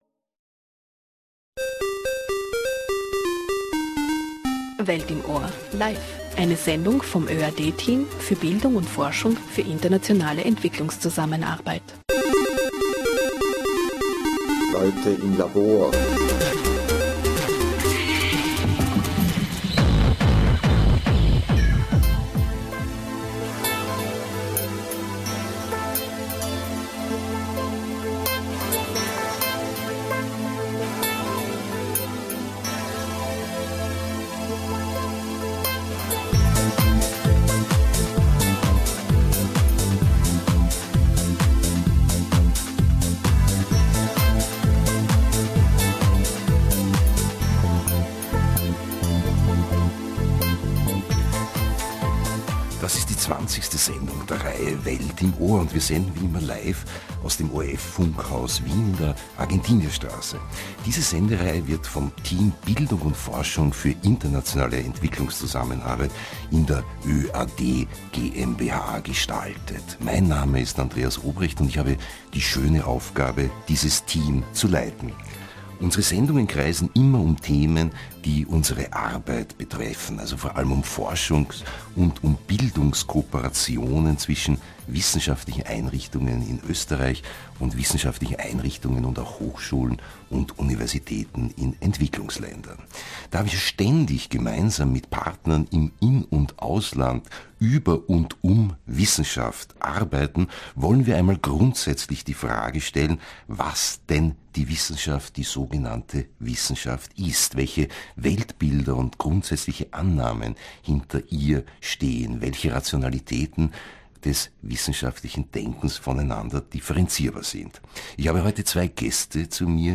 Ein Gespr�ch �ber die Grundlagen des wissenschaftlichen Denkens Eine Sendung vom ORF Campusradio am 20.1.2012